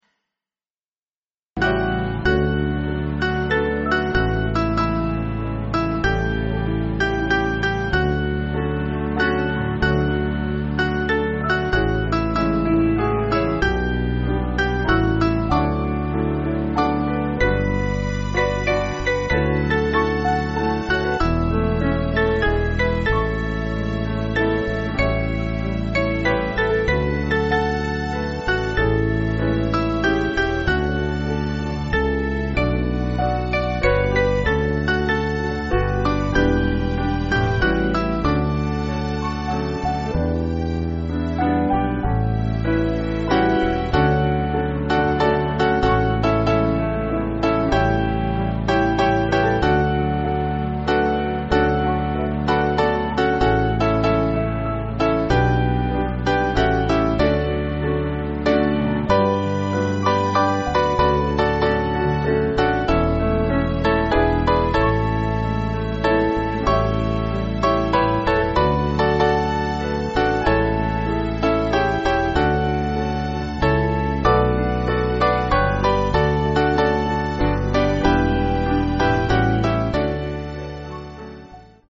8.6.8.6.D
Mainly Piano